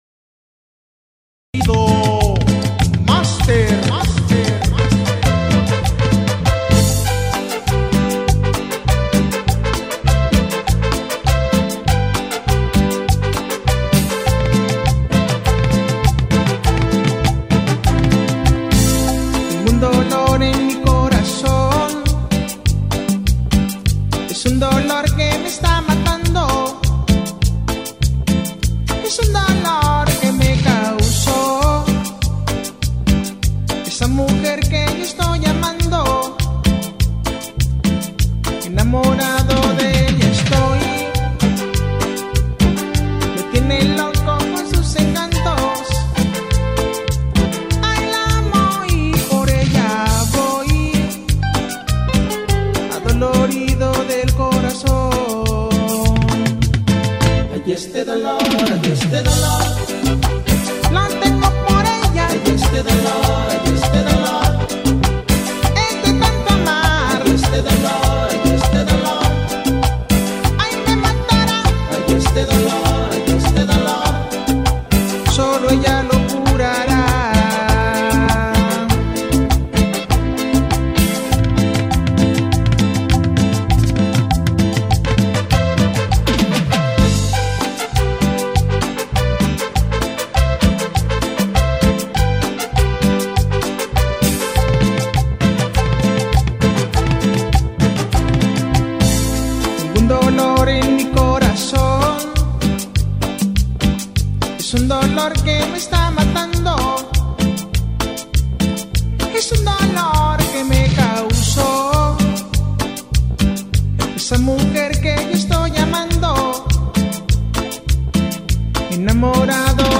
gracias a un estilo que siempre suena fresco y moderno.
musica tropi-romantica